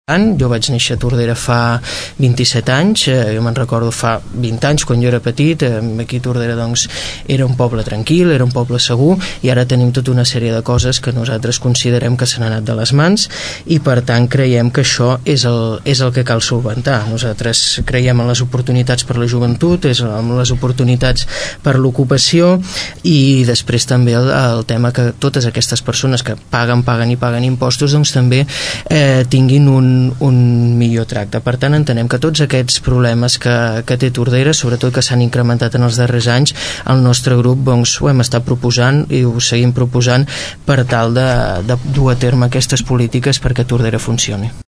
debat pp
debat-pp.mp3